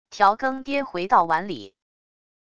调羹跌回到碗里wav音频